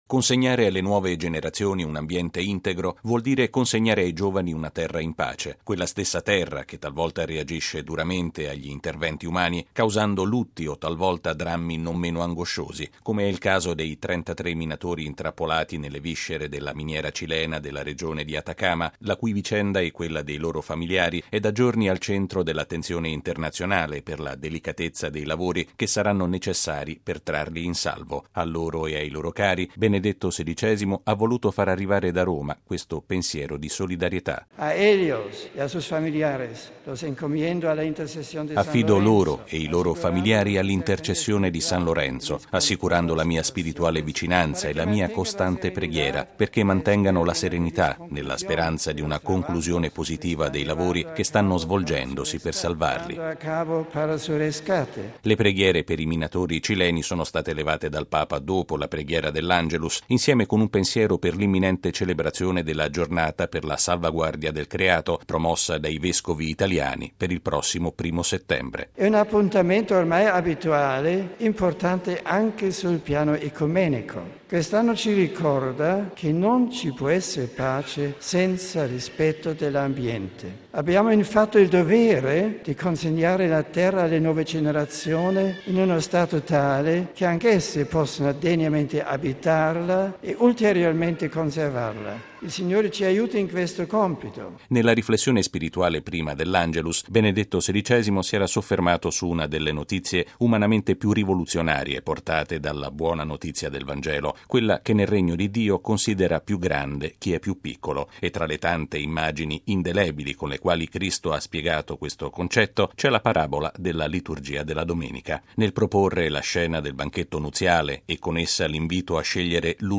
Benedetto XVI l’ha levata questa mattina, dopo la preghiera dell’Angelus a Castel Gandolfo, dedicandola ai minatori cileni da giorni al centro di un complesso intervento per riportarli alla luce dopo il crollo che li ha intrappolati a decine di metri di profondità nella regione di Atacama.